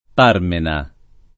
vai all'elenco alfabetico delle voci ingrandisci il carattere 100% rimpicciolisci il carattere stampa invia tramite posta elettronica codividi su Facebook Parmena [ p # rmena ] (meglio che Parmenas [ p # rmena S ; alla greca parmen #S ]) pers. m. bibl.